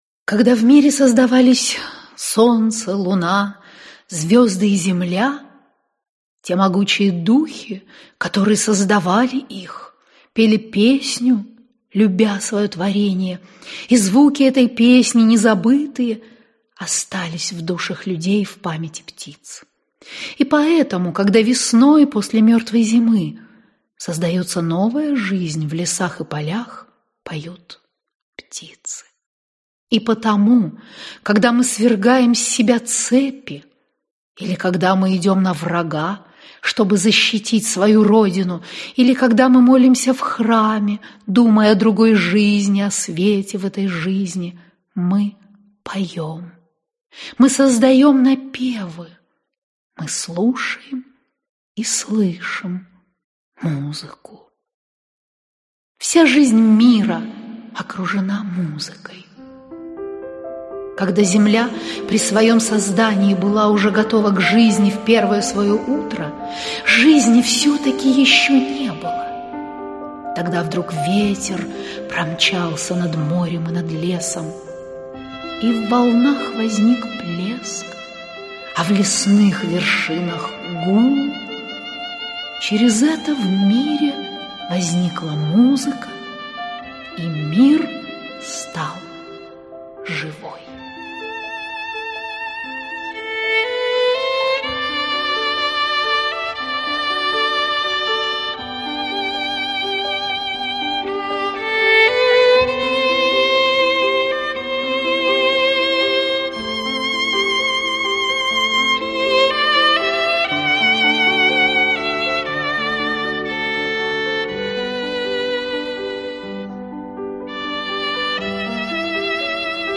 Аудиокнига Музыкой возвышенное слово | Библиотека аудиокниг